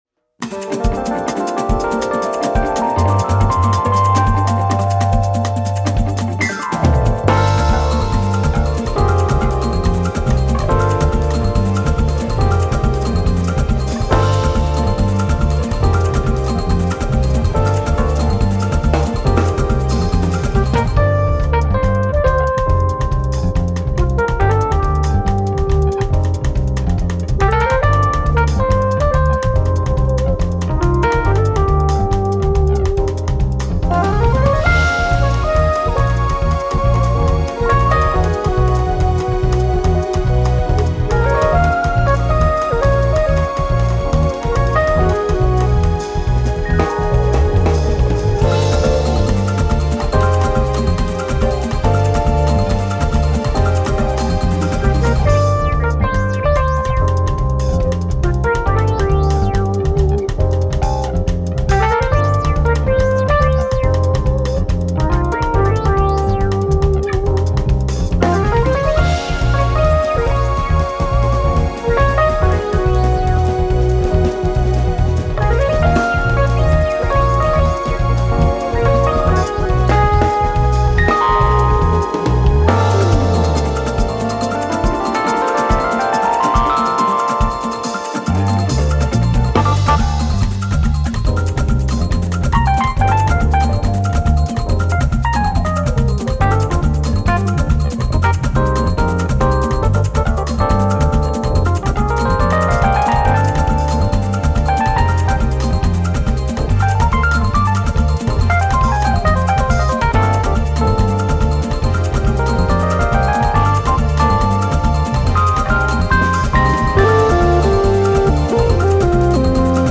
élan brésilien féerique